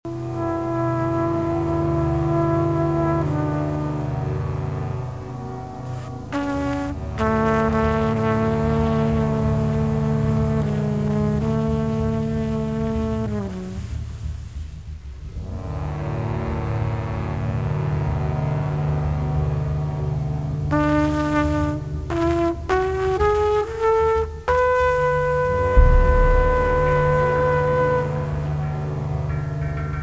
tromba elettrica e acustica, voci, loops, bass synth